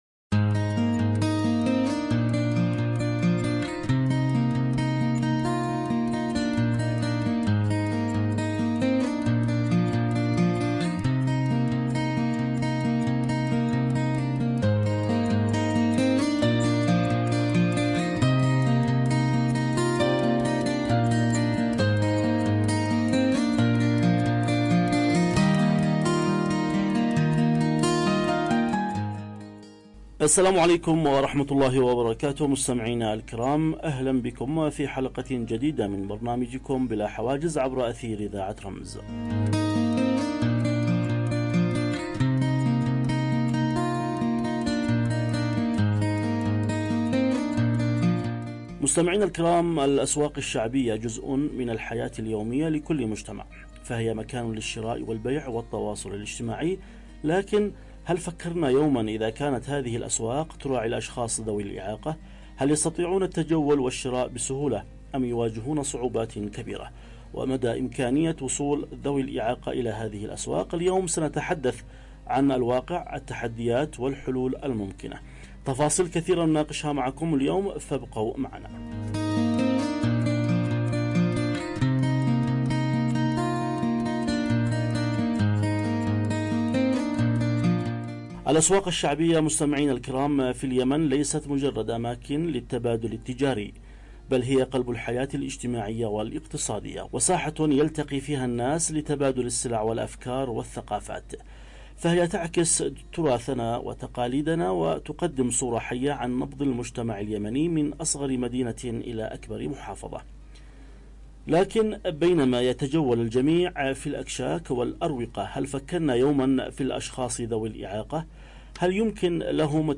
📻 عبر أثير إذاعة رمز